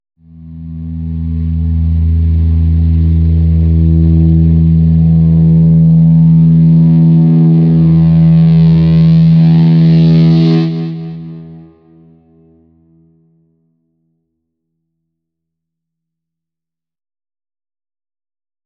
Гудение электрогитары возле колонок